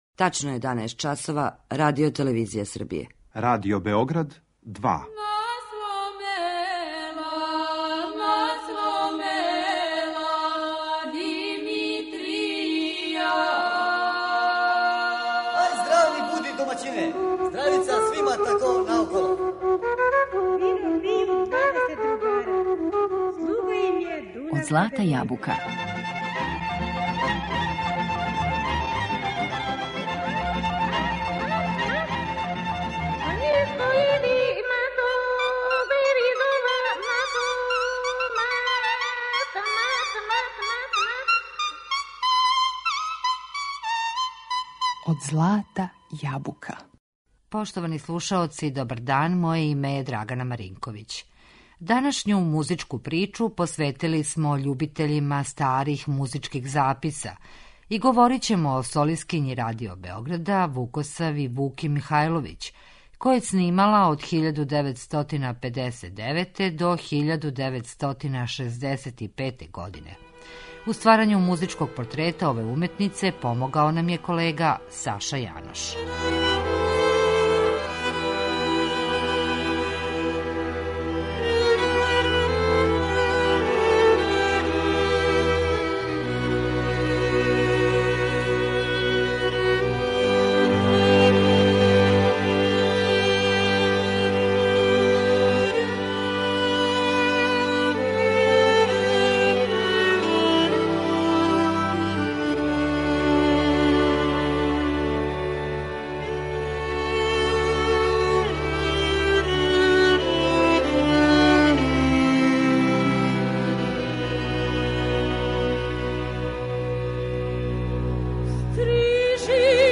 Данашњу музичку причу посветили смо љубитељима старих музичких записа.